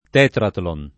vai all'elenco alfabetico delle voci ingrandisci il carattere 100% rimpicciolisci il carattere stampa invia tramite posta elettronica codividi su Facebook tetrathlon [ t $ tratlon ] (meglio che tetratlon [ id. ]) s. m. (sport.)